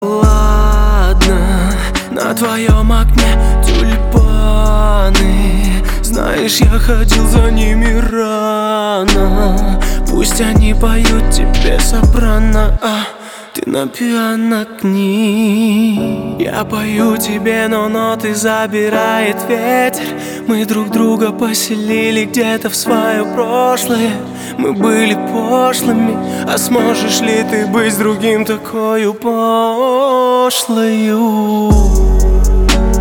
• Качество: 320, Stereo
мужской вокал
лирика
спокойные